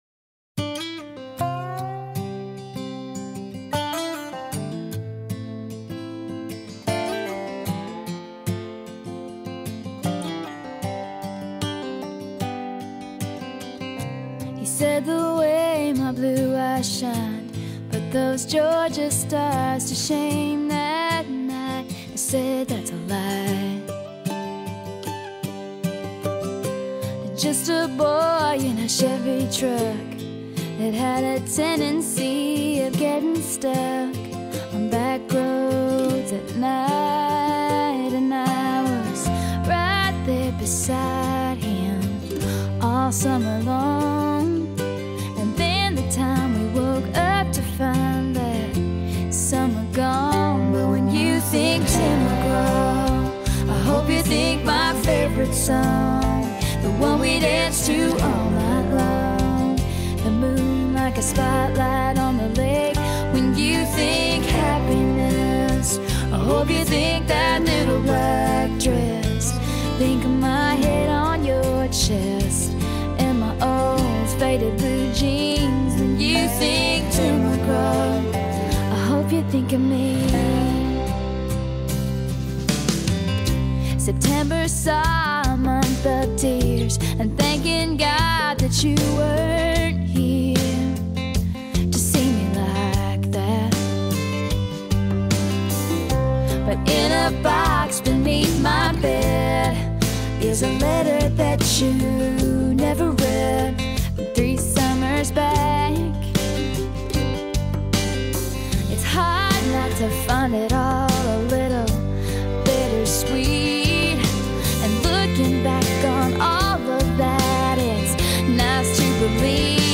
Country, Pop